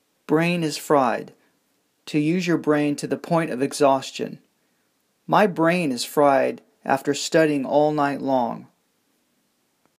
マンツーマンのレッスン担当の英語ネイティブによる発音は下記のリンクをクリックしてください。